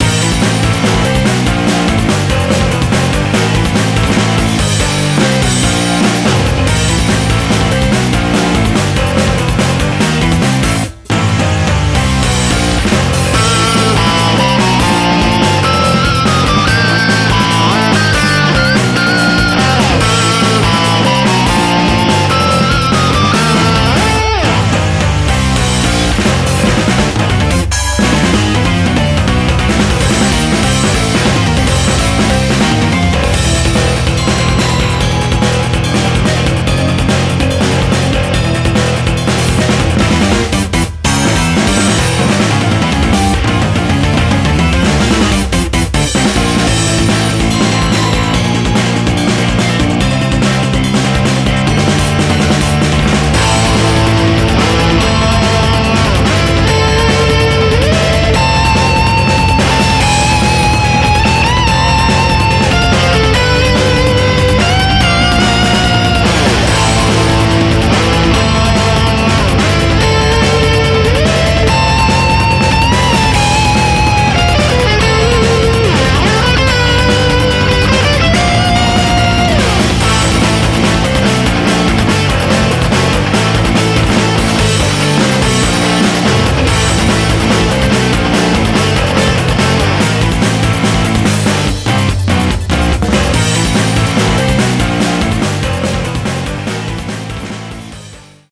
BPM72-144
Audio QualityMusic Cut